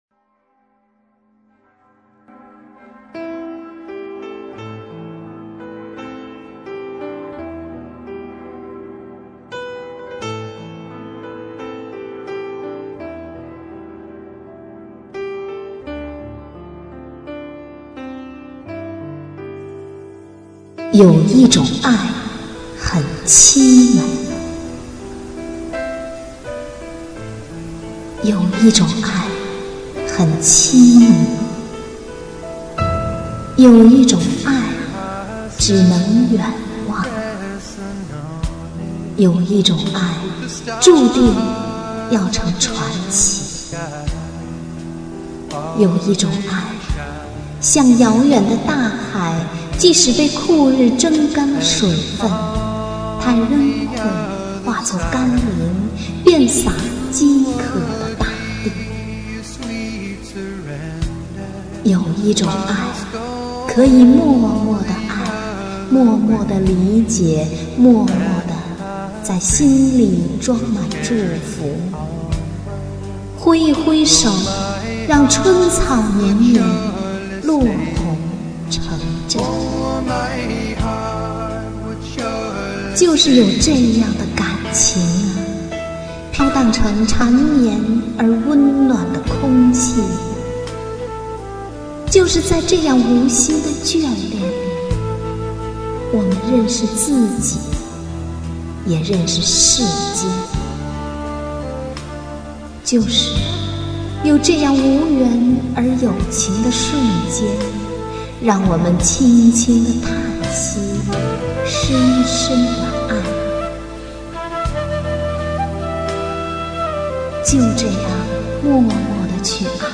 有一种爱很凄美朗诵版